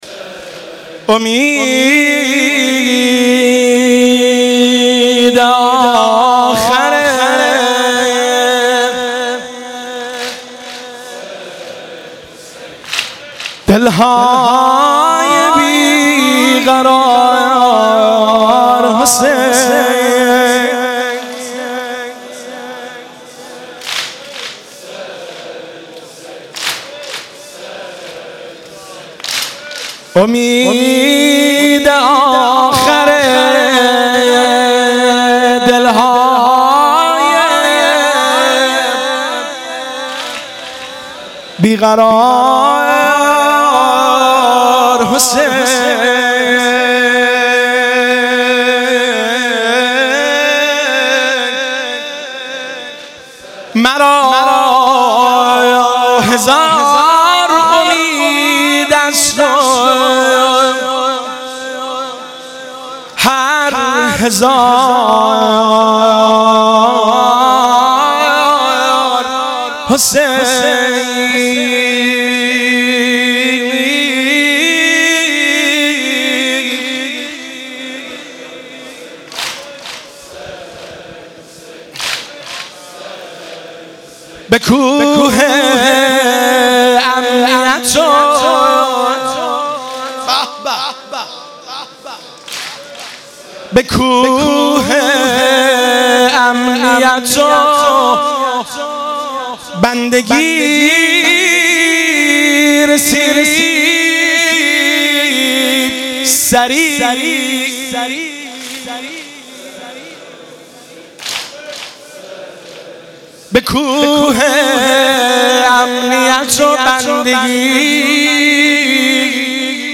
شب اول محرم - به نام نامی حضرت مسلم(ع)
محرم 96 - شعرخوانی - امید آخر دل های بی قرار حسین